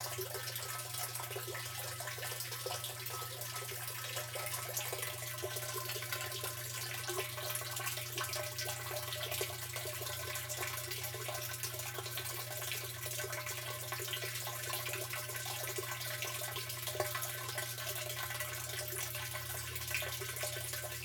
Test of the random (free) recorder from the city Material Reuse center today. The sound of cool water inside of my system (albeit... just growing , who are mostly fertilizing .